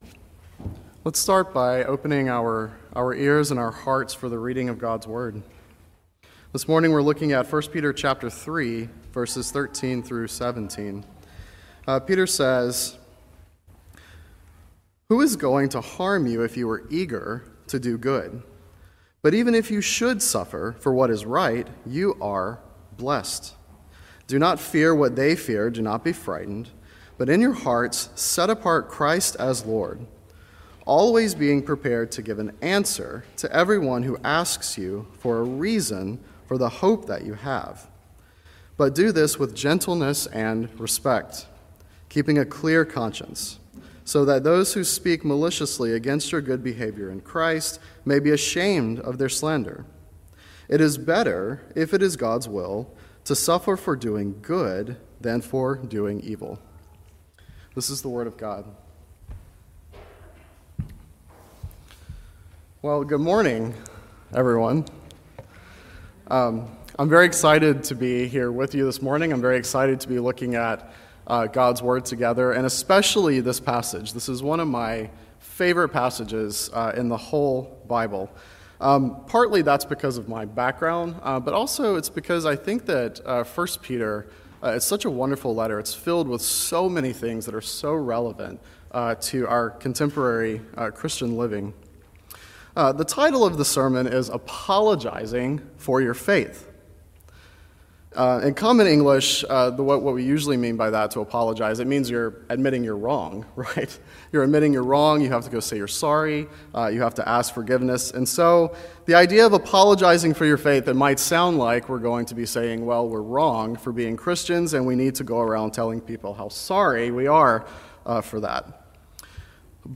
Guest Preachers